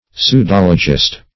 Pseudologist \Pseu*dol"o*gist\, n.